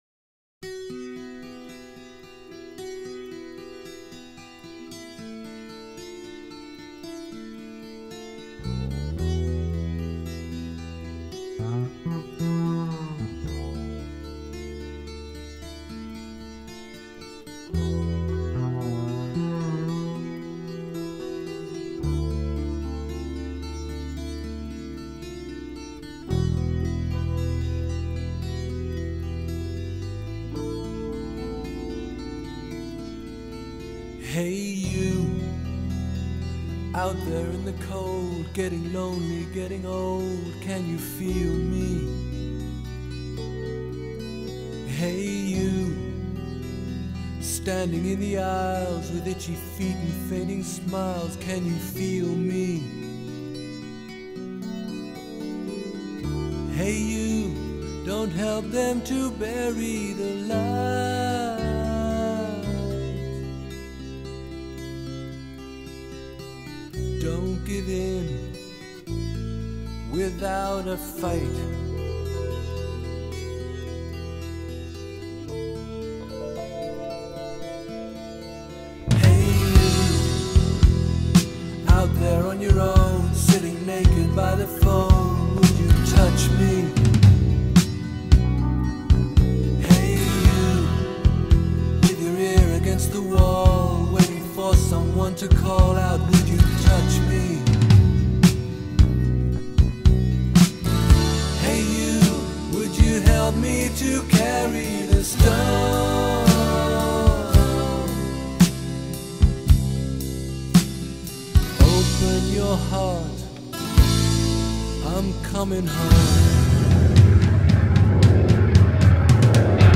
Progressive Rock, Psychedelic Rock